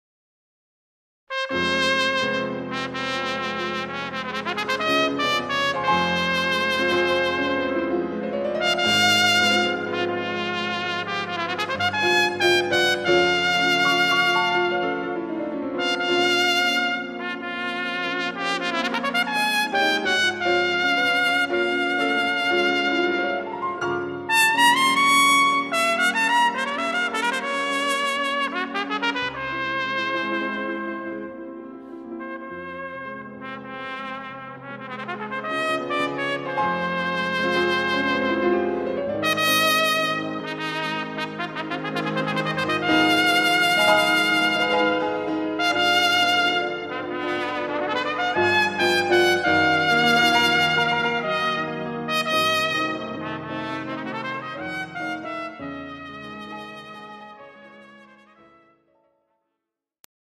Besetzung: Instrumentalnoten für Trompete